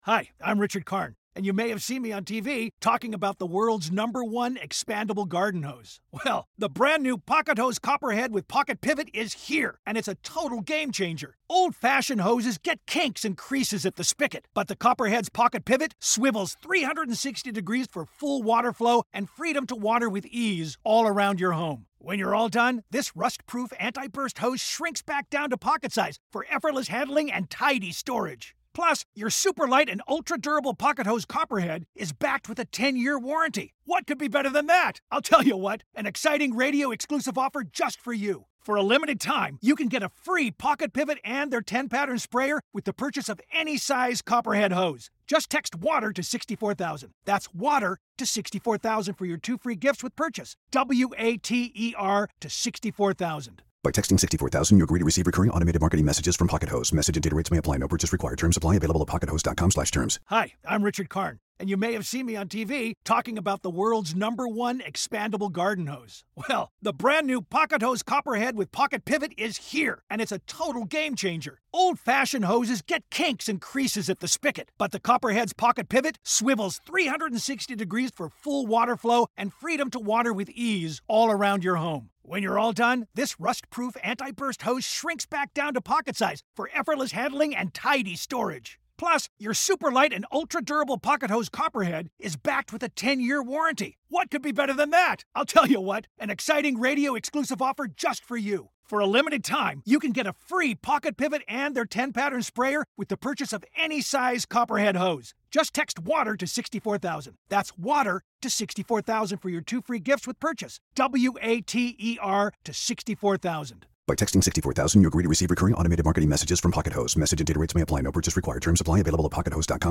Interview with Mortal Kombat Creator, Ed Boon